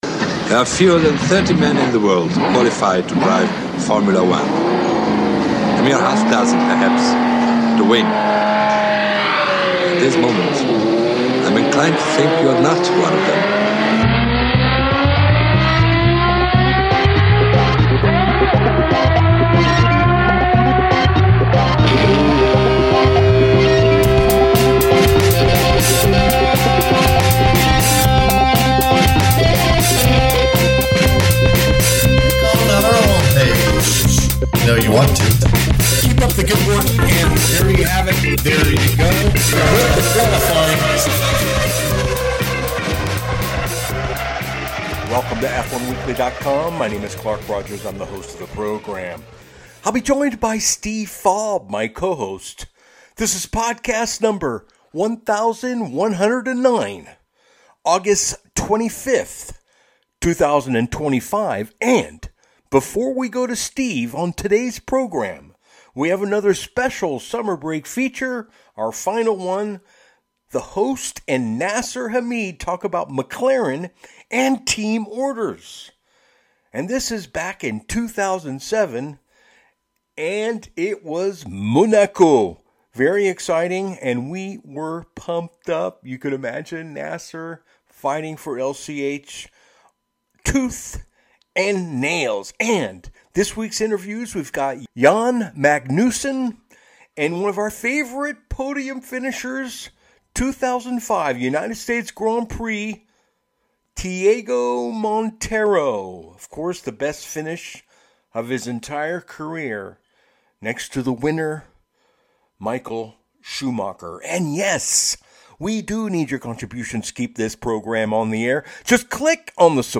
Our interviews this week…Jan Magnussen, and from the 2005 USGP podium finishers Tiego Monteiro!